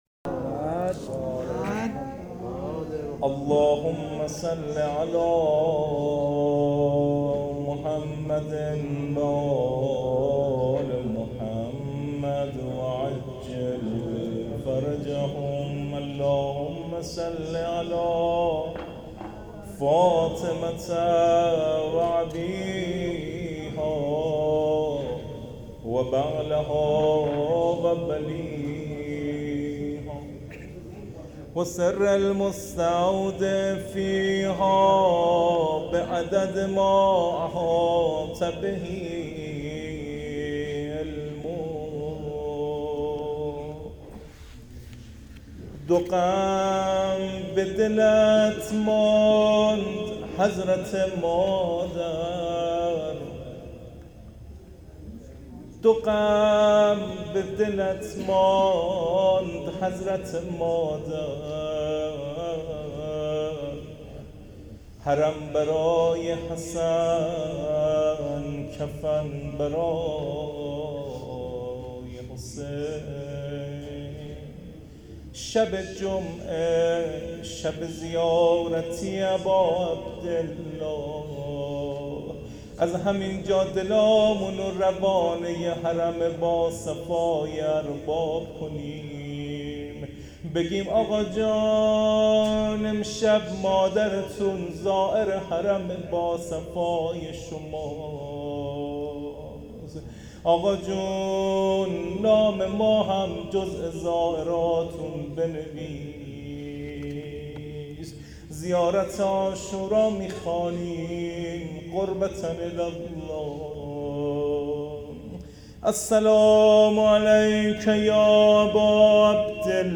زیارت عاشورا و روضه شهادت حضرت زهرا س